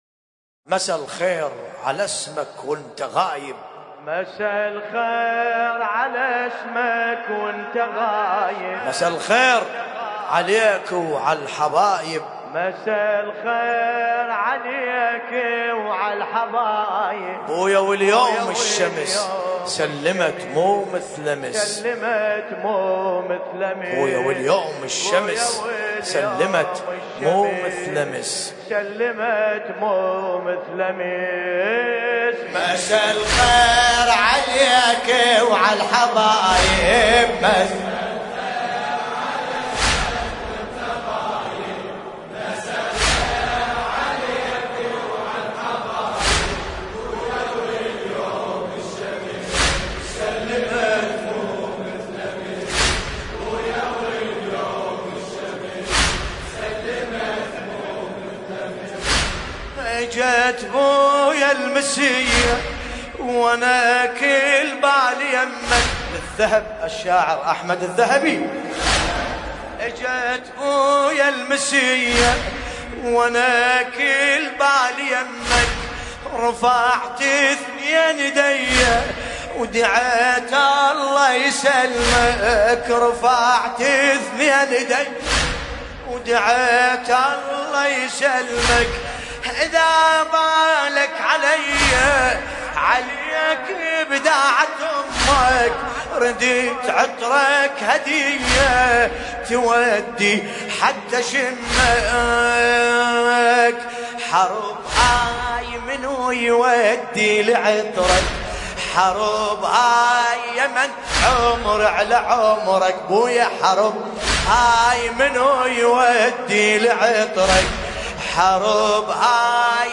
ملف صوتی مساء الخير بصوت باسم الكربلائي